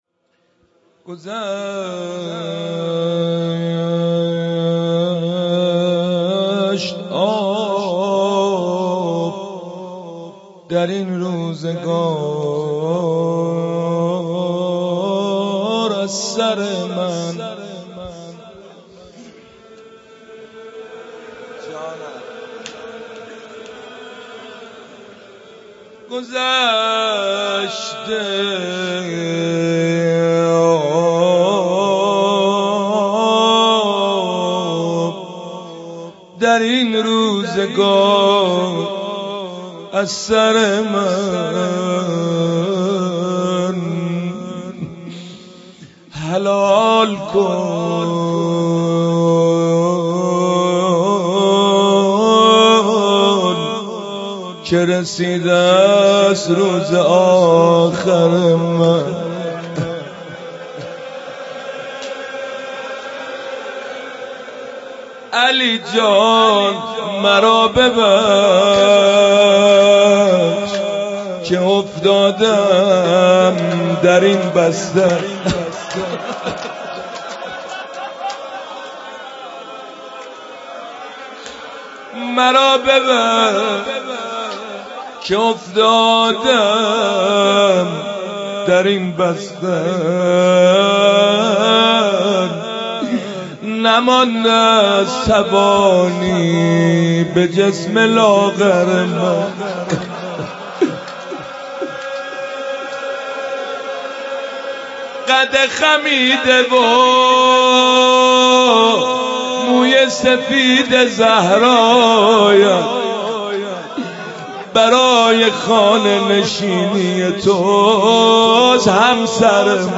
روضه وتوسل به حضرت زهرا(س)
دانلود مداحی